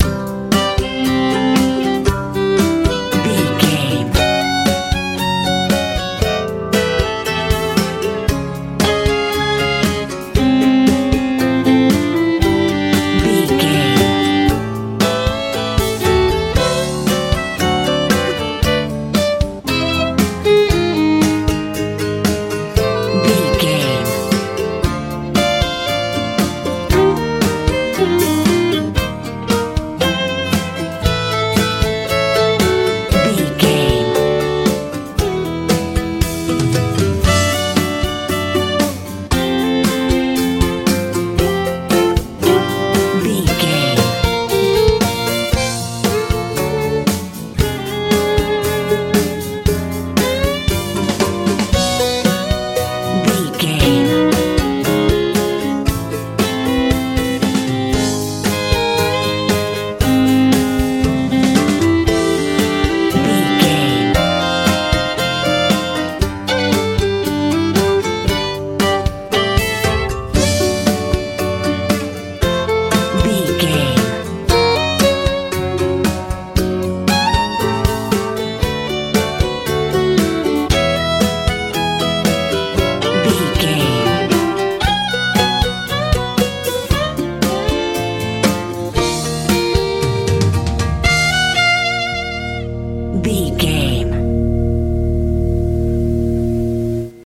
lite pop feel
Ionian/Major
sweet
playful
violin
acoustic guitar
bass guitar
drums
relaxed